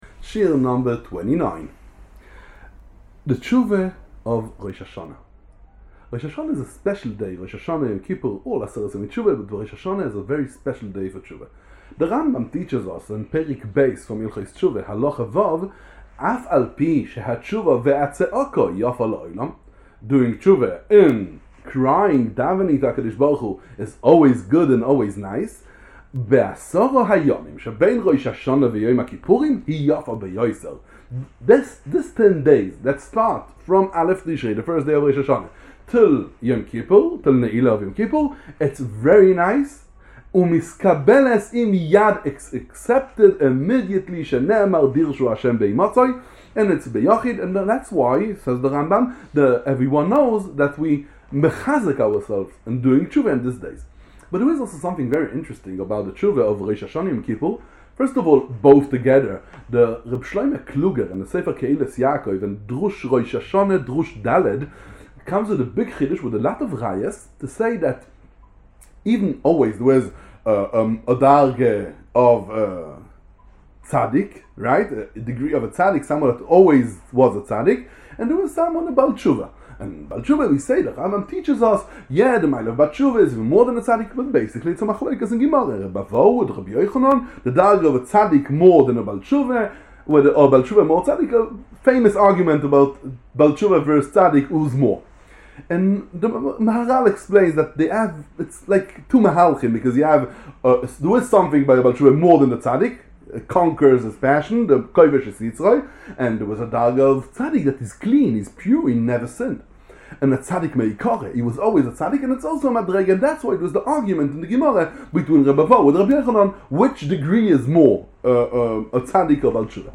A series of short lessons on the mitzvah of Teshuvah, every day, from the beginning of Elul to Yom Kippur, 40 days בעז"ה.